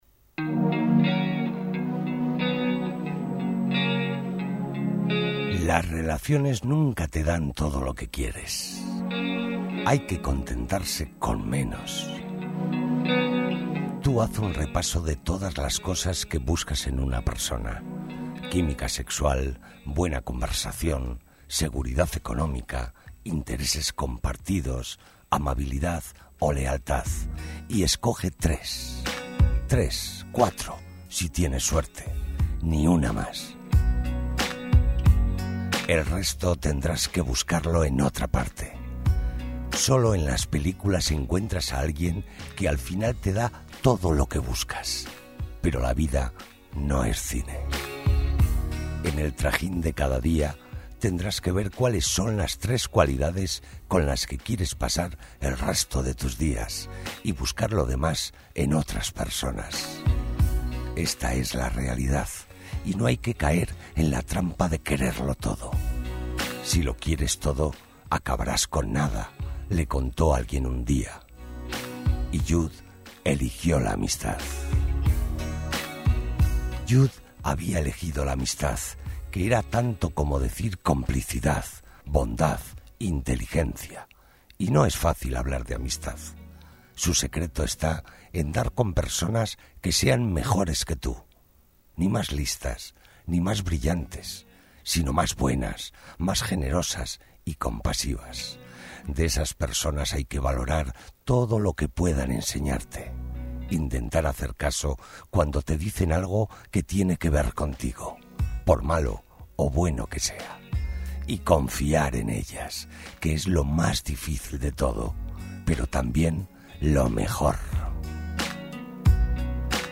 Locutor profesional, más de 30 años de experiencia en radio, televisión , publicidad y márketing
kastilisch
Sprechprobe: Sonstiges (Muttersprache):